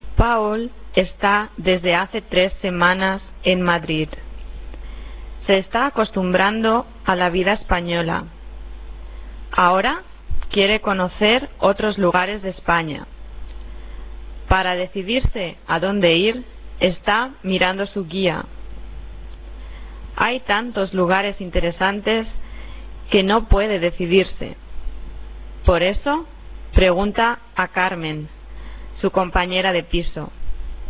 Die beiden Audiodateien dieser Lektion sind dem schon erwähnten Spanischkurs entnommen.